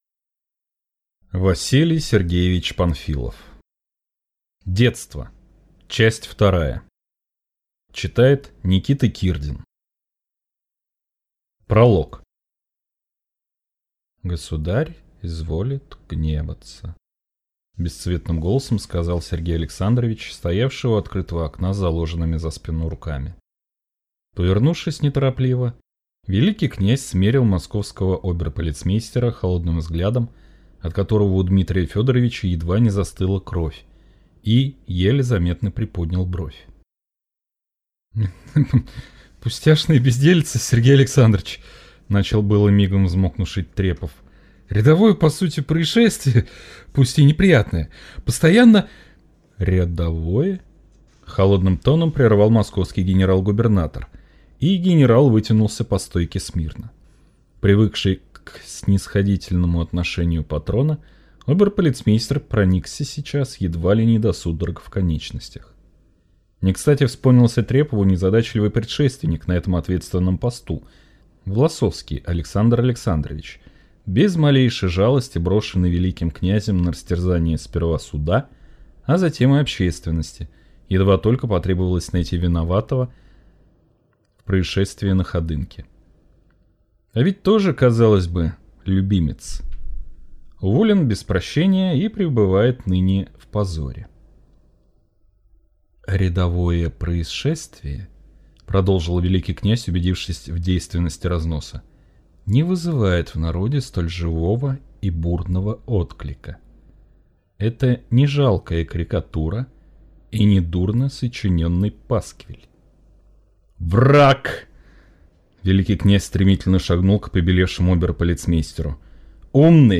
Аудиокнига Детство 2 | Библиотека аудиокниг